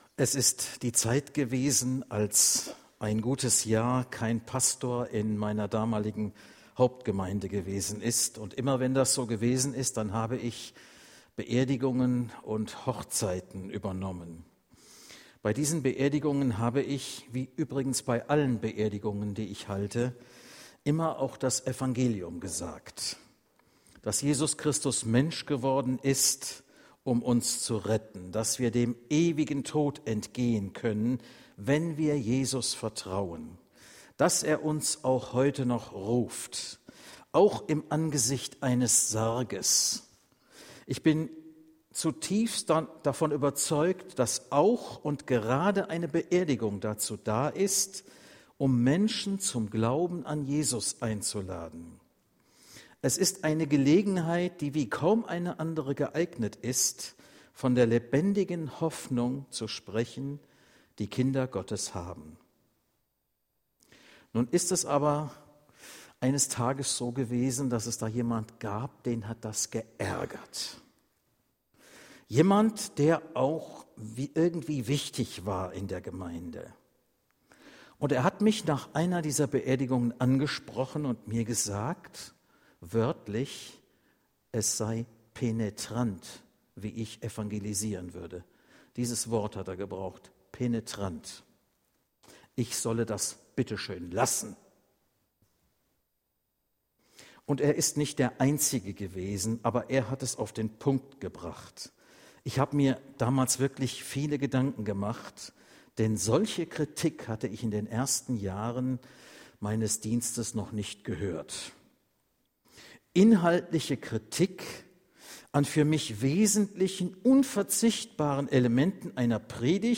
Auf dieser Seite finden sie alle Predigten, die 2019 in der EFG Parsau gehalten wurden: